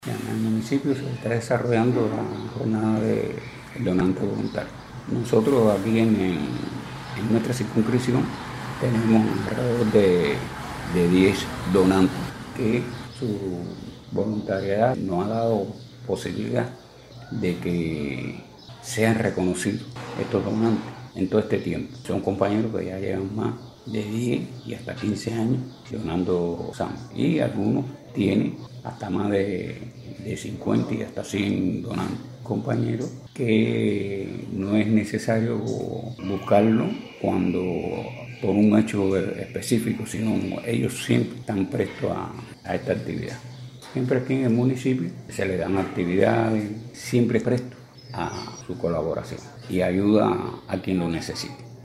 Sobre esta celebración y, en especial, su materialización en el municipio de Pedro Betancourt conversamos